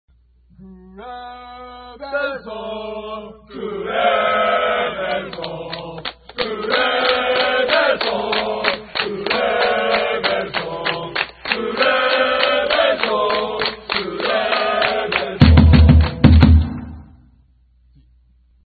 選手のコール